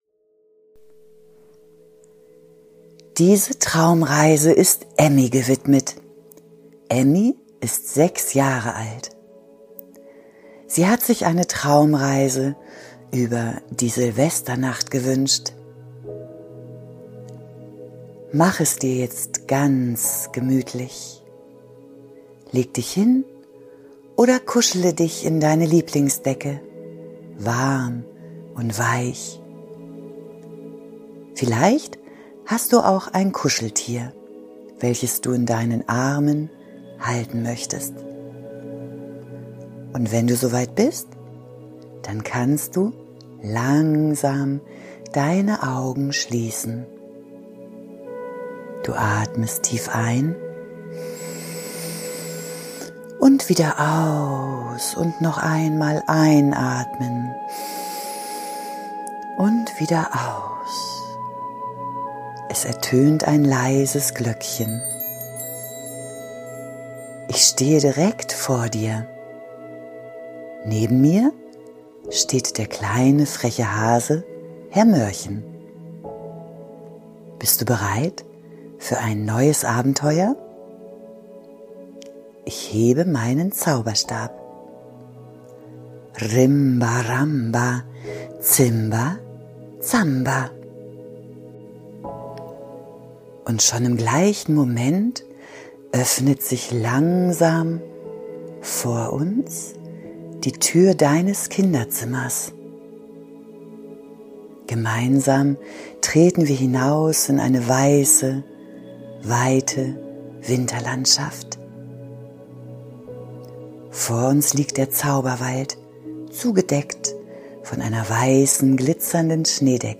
Eine liebevolle Einschlafgeschichte die